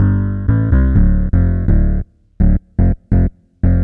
cch_bass_loop_sanfierro_125_F.wav